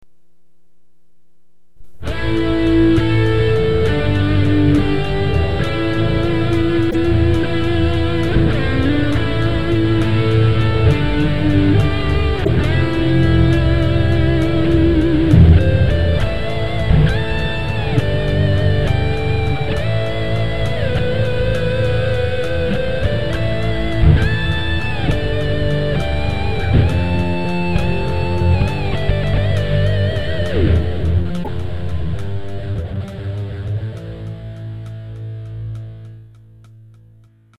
Príjemná melódia, pekne zahratá.